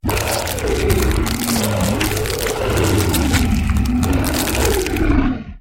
Здесь вы найдете реалистичные аудиозаписи воя, рычания и других эффектов, связанных с этими легендарными существами.
Шум оборотня, превращающегося в человека